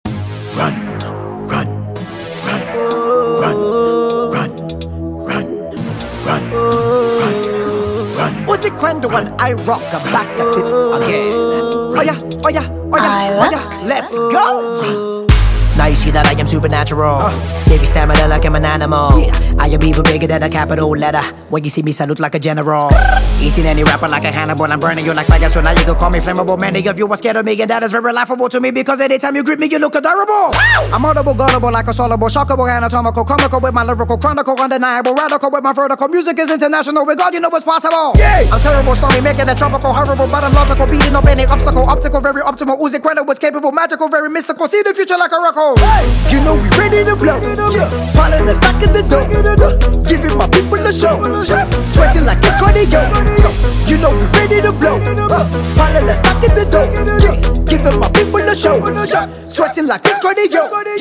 ” is filled with hard-hitting punchlines.